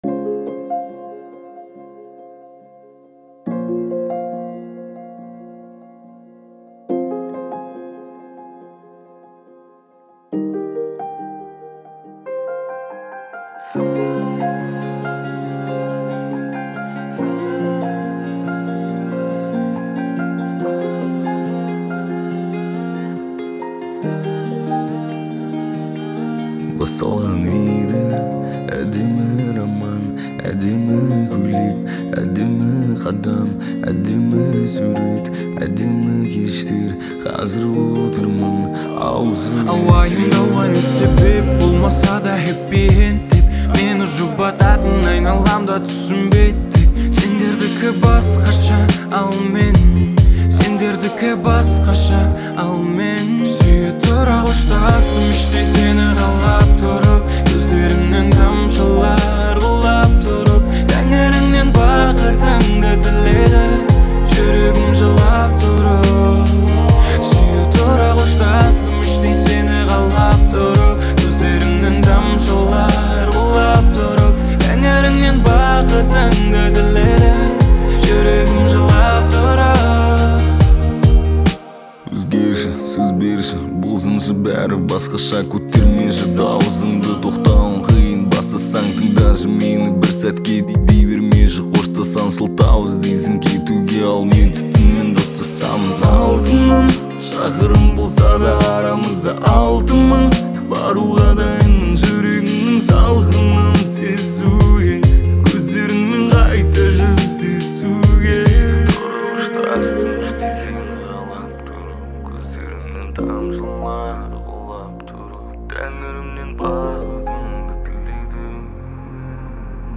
Кавказская музыка